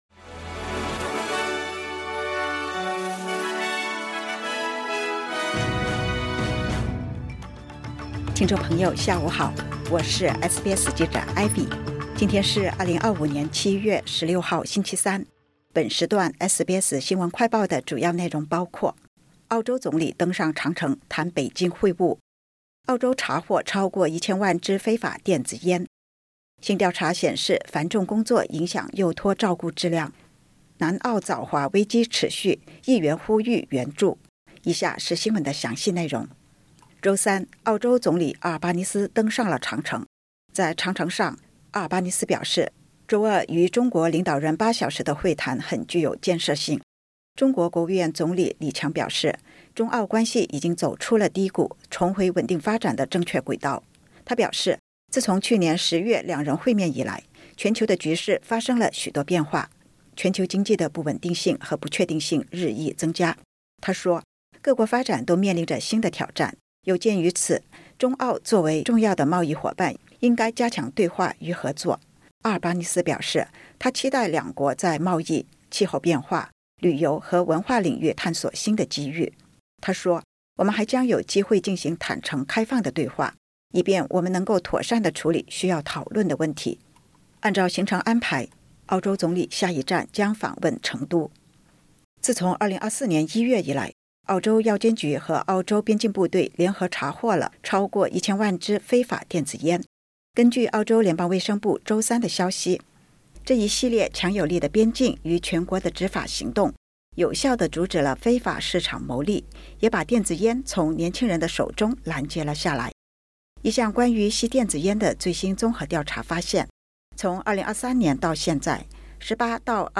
【SBS新闻快报】澳洲总理阿尔巴尼斯登上长城谈北京会晤